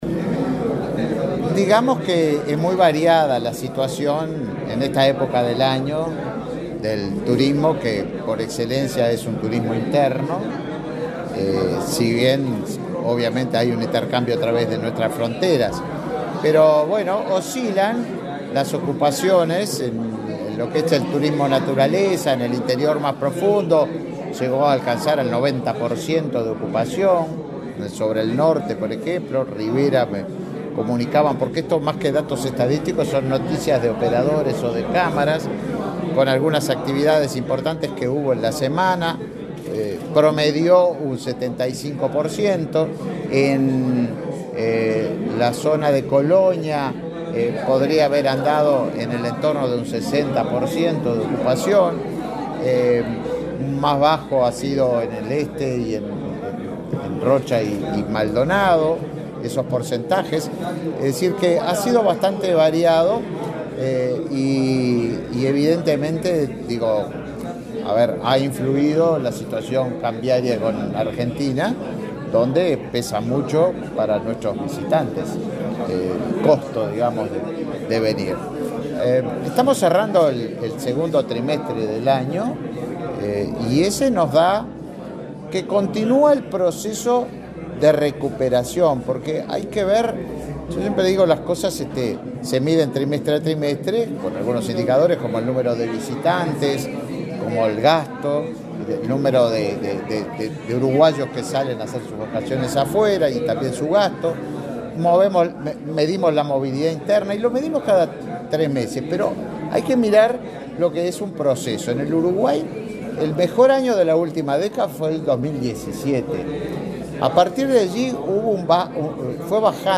Declaraciones del ministro de Turismo, Tabaré Viera
Declaraciones del ministro de Turismo, Tabaré Viera 24/07/2023 Compartir Facebook X Copiar enlace WhatsApp LinkedIn El subsecretario de Turismo, Remo Monzeglio, y el titular de la cartera, Tabaré Viera, participaron en el lanzamiento de Termatalia, una feria internacional de turismo termal, que se desarrollará entre el 4 y el 6 de octubre en los departamentos de Paysandú y Salto. Luego Viera dialogó con la prensa.